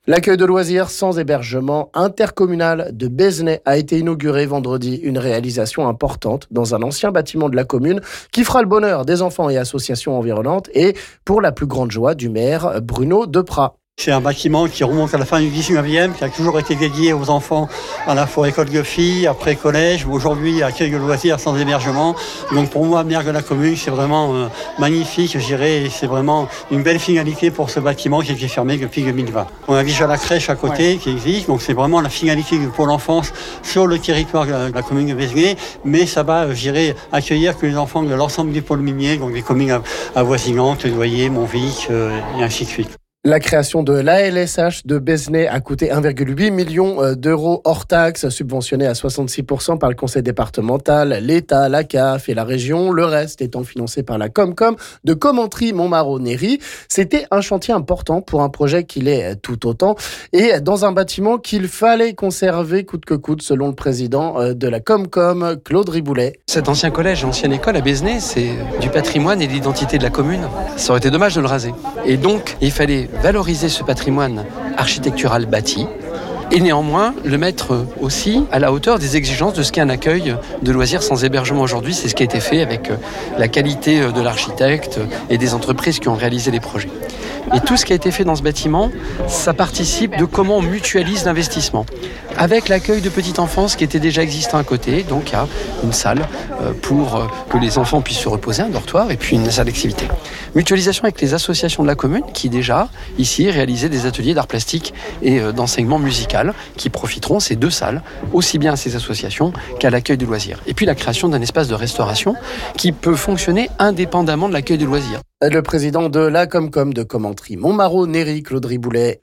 Le maire de Bézenet et le président de la Comcom sont à écouter ici...